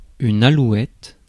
Ääntäminen
Ääntäminen France: IPA: [a.lu.ɛt] Tuntematon aksentti: IPA: /a.lwɛt/ Haettu sana löytyi näillä lähdekielillä: ranska Käännös Substantiivit 1. lőoke 2. lõoke Suku: f .